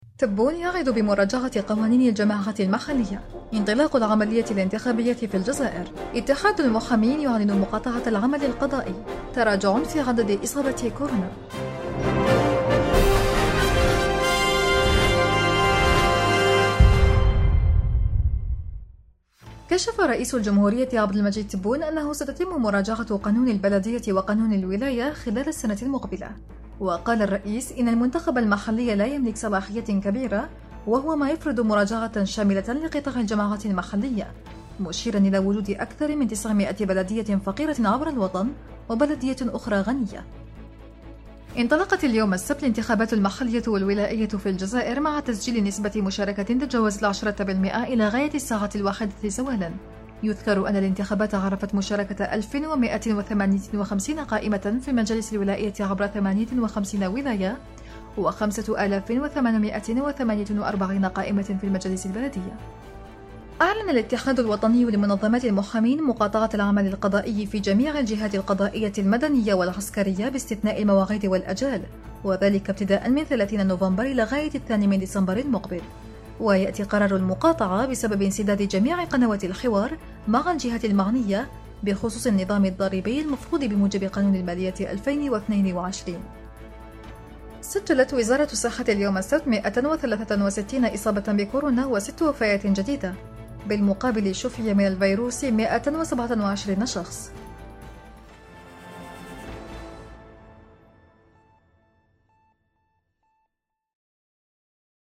النشرة الرقميةفي دقيقتين